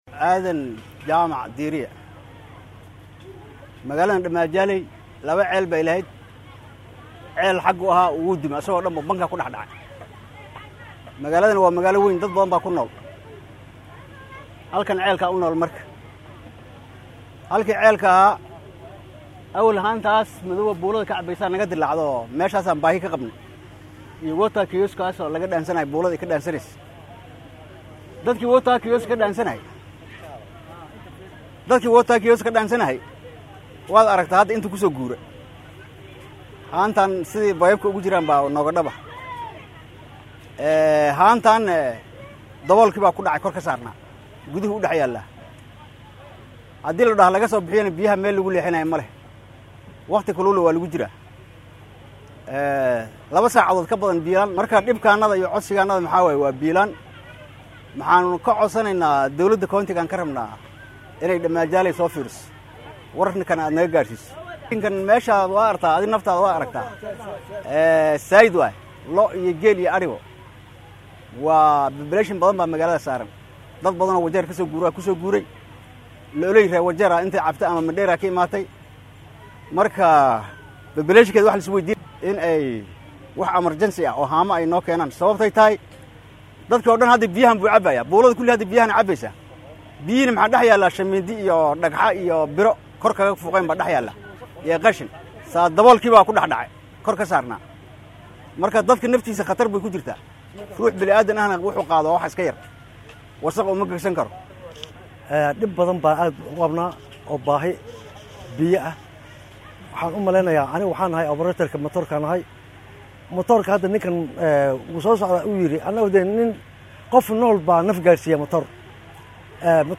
Qaar ka mid ah shacabka oo warbaahinta la hadlay ayaa dareenkooda sidan u muujiyay.